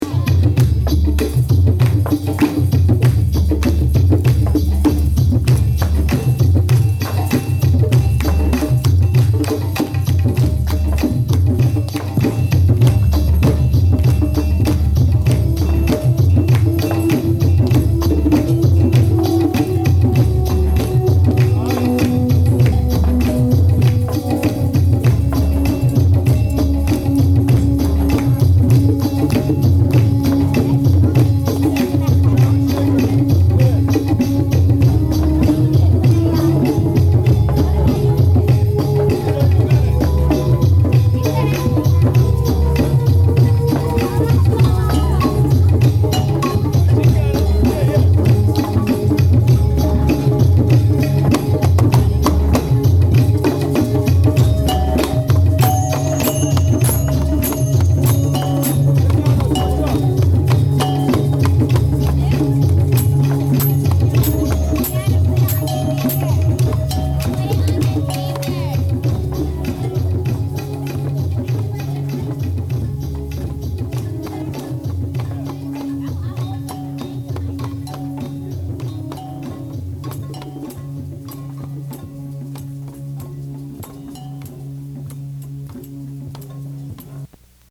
Live recordings from Stetson!
Drumming with the Kids!
drumsflutekids.mp3